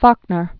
(fôknər), William 1897-1962.